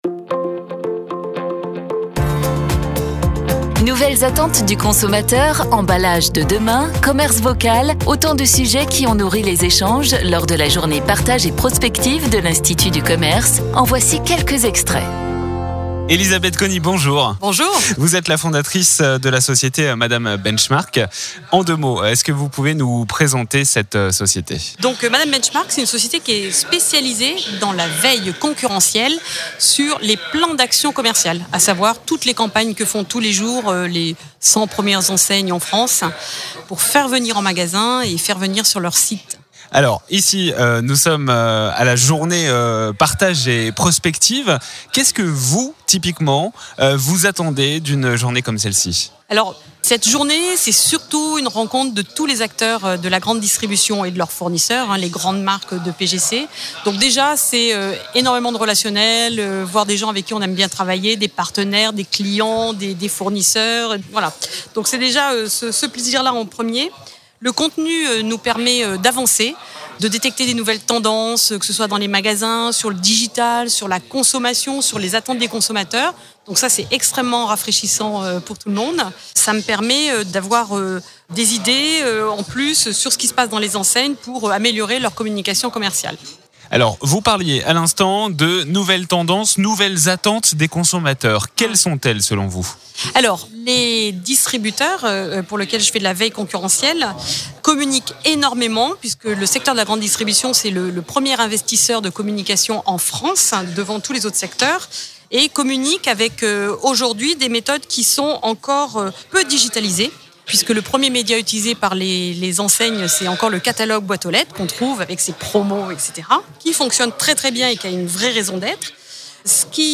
Les interviews Mediameeting de la Journée Partage et Prospective 2019